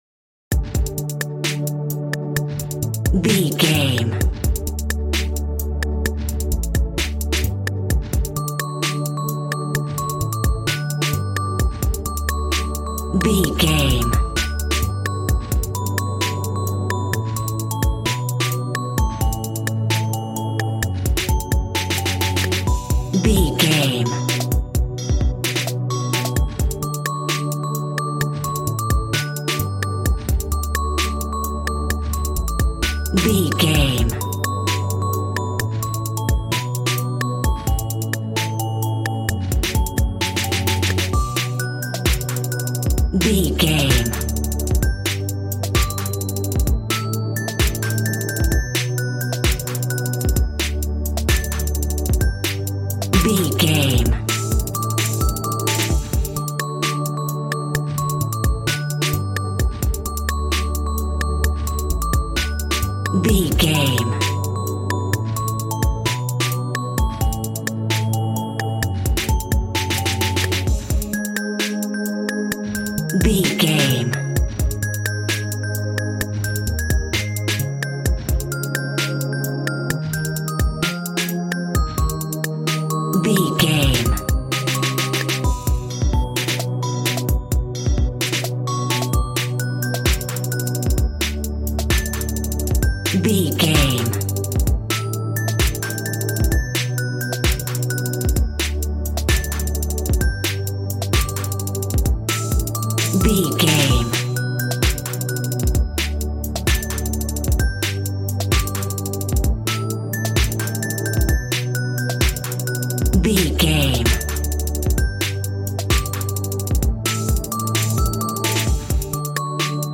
Ionian/Major
Fast
groovy
synthesiser
drums
piano